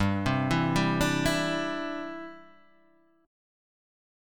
G6sus chord